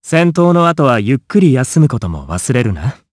Siegfried-Vox_Victory_jp.wav